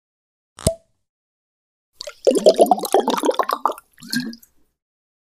Звуки пробки бутылки
Вытащили пробку и залили жидкость